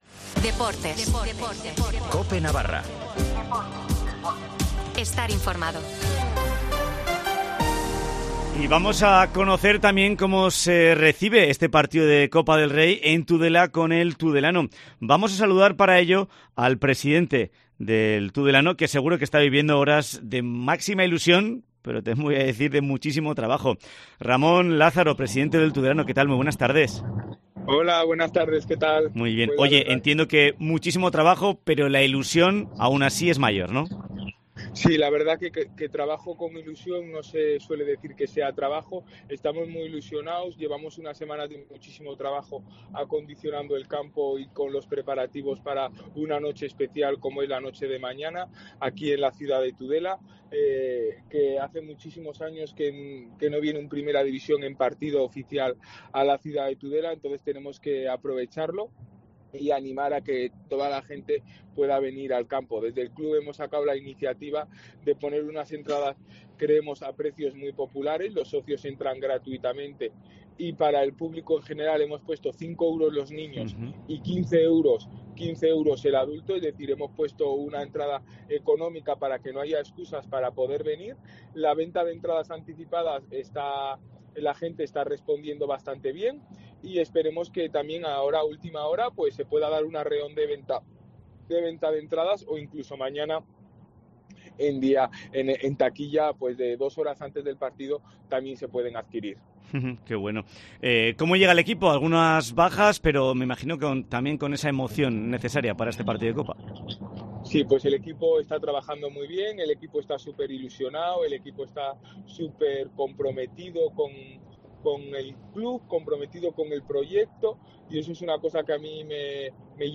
habla en COPE en la previa del partido de Las Palmas